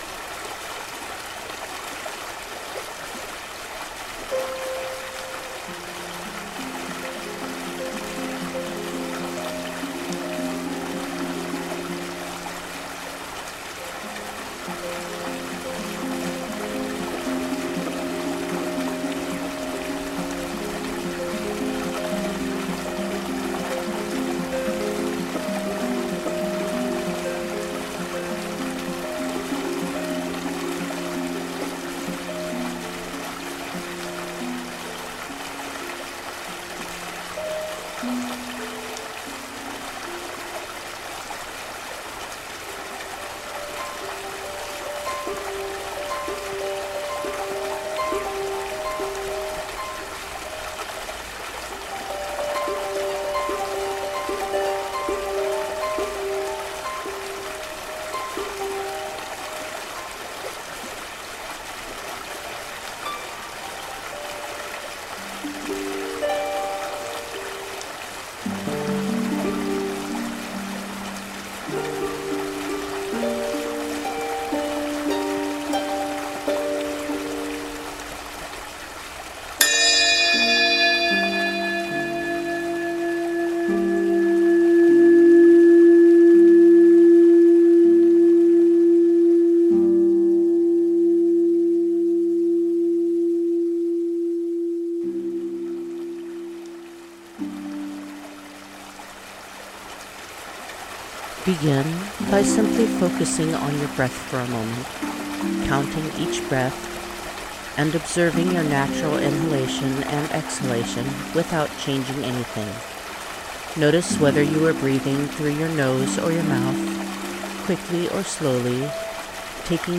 The guided meditation track uses a chime to begin this meditation, as well as most of the meditations in this series.
GuidedMeditation-centering_simple-no-intro.mp3